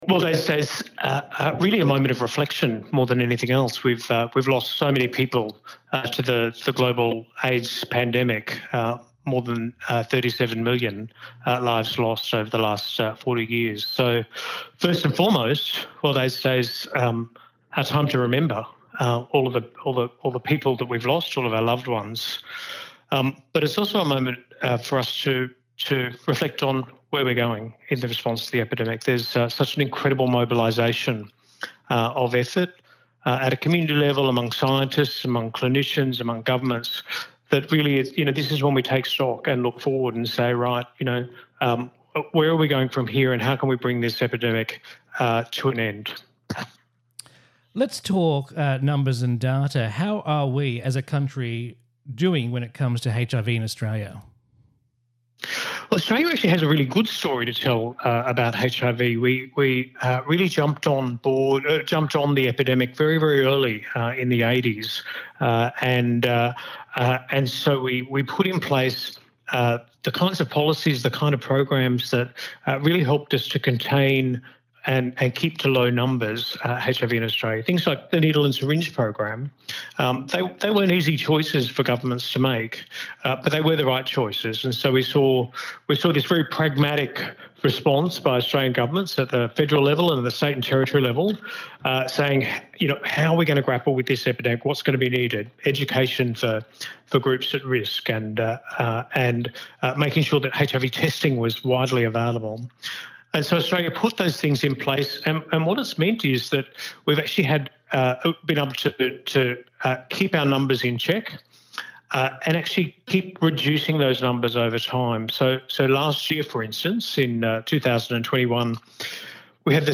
INTERVIEW | World AIDS Day 2022